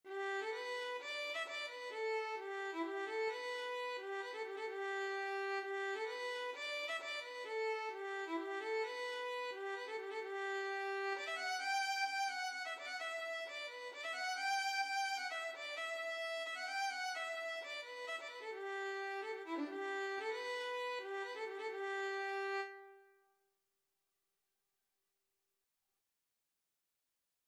Violin version
3/4 (View more 3/4 Music)
G major (Sounding Pitch) (View more G major Music for Violin )
Violin  (View more Intermediate Violin Music)
Traditional (View more Traditional Violin Music)
Irish
young_mans_dream_ON382_vln.mp3